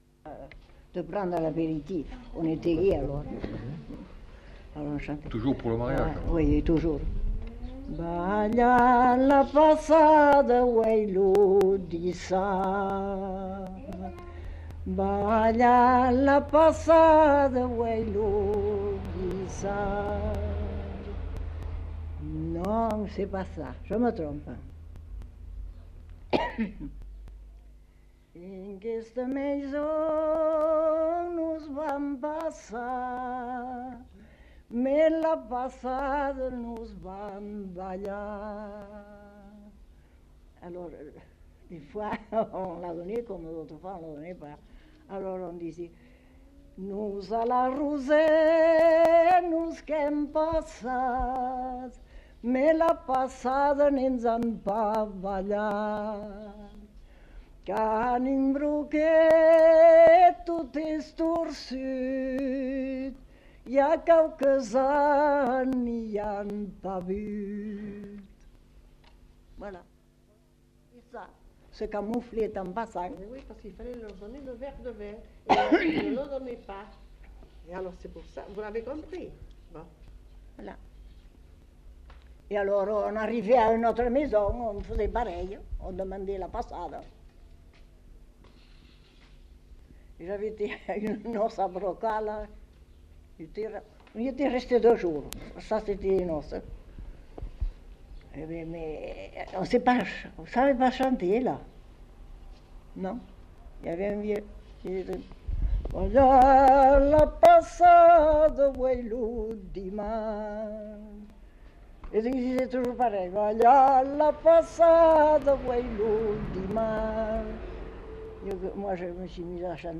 Lieu : Mont-de-Marsan
Genre : chant
Type de voix : voix de femme
Production du son : chanté